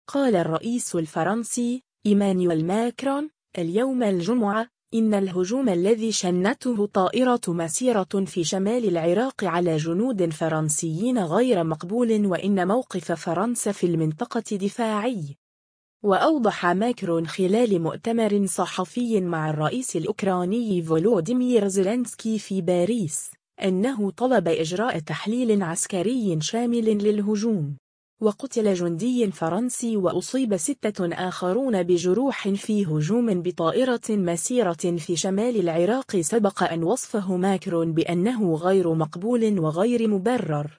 وأوضح ماكرون خلال مؤتمر صحفي مع الرئيس الأوكراني فولوديمير زيلينسكي في باريس، أنه طلب إجراء تحليل عسكري شامل للهجوم.